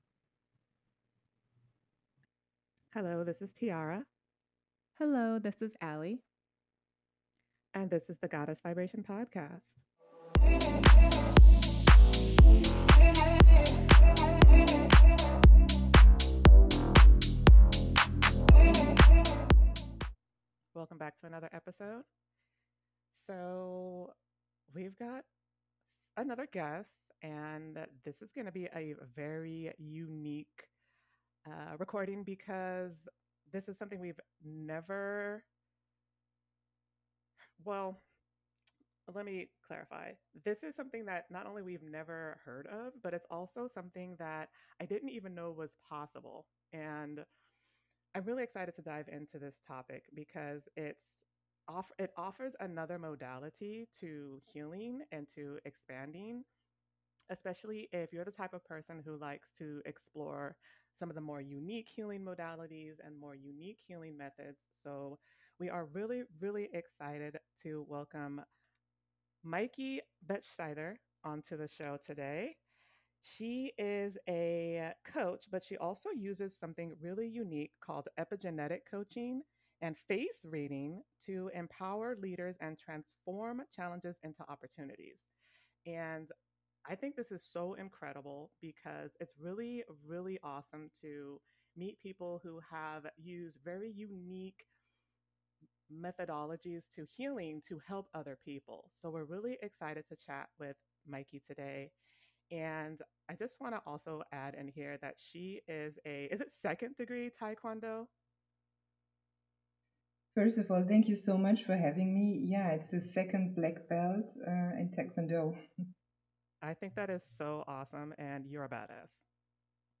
Two best friends share their experiences on life, love and mysticism on a journey of self improvement.
No topic is off limits with these two ladies who often use colorful language and humor to share their profound tales of interaction with gods, angels and other divine beings, divulge tips and tricks to help others navigate their own self improvement, and discuss conspiracies and controversial social topics that that only the bravest would take on.